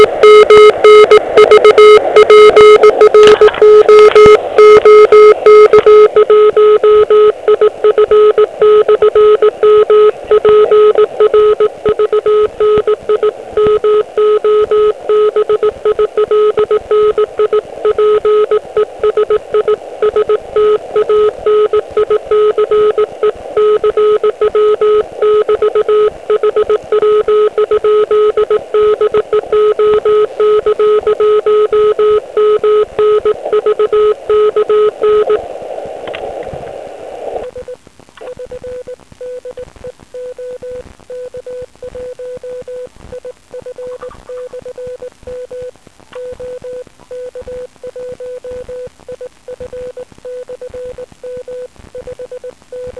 Provedli jsme vlastně spojení ještě za velice "hutné" vrstvy D a to odrazem od F1. Útlum tedy musel být obrovský.